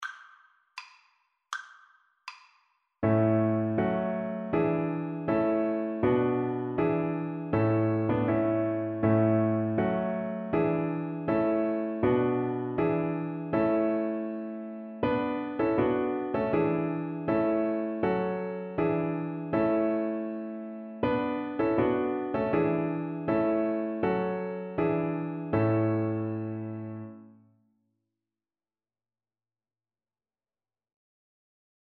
Violin
A major (Sounding Pitch) (View more A major Music for Violin )
Andante
2/4 (View more 2/4 Music)
E5-E6
Traditional (View more Traditional Violin Music)
Israeli